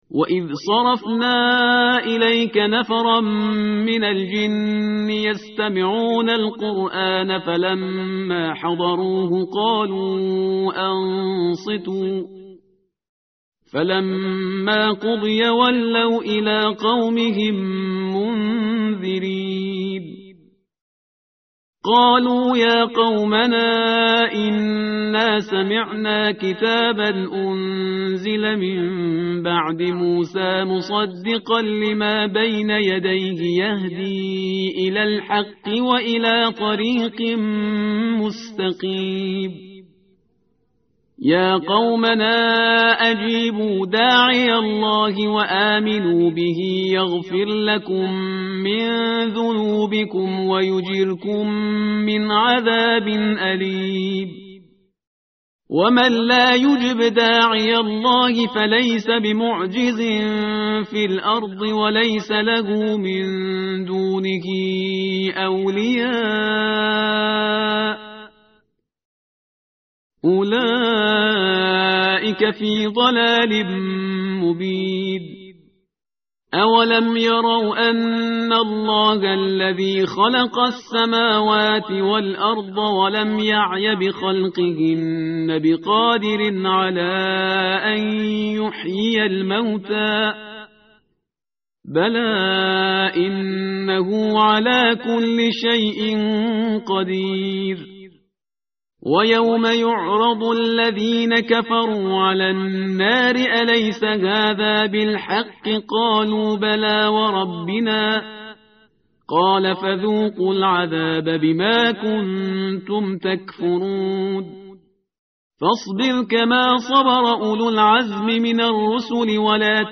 متن قرآن همراه باتلاوت قرآن و ترجمه
tartil_parhizgar_page_506.mp3